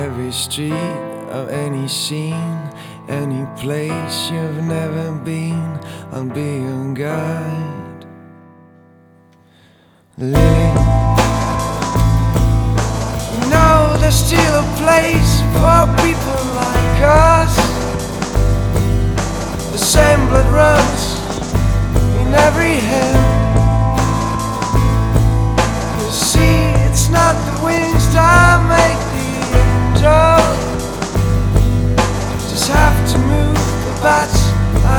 Жанр: Иностранный рок / Рок / Инди / Альтернатива